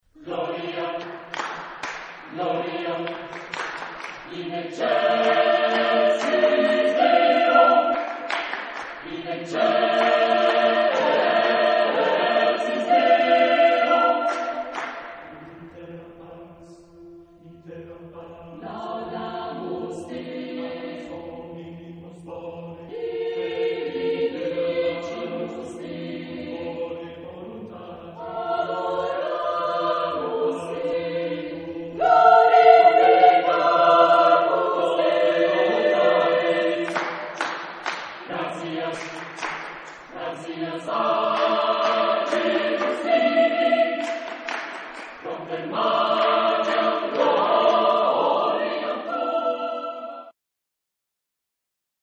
Genre-Style-Forme : Sacré ; Messe
Type de choeur : mixtes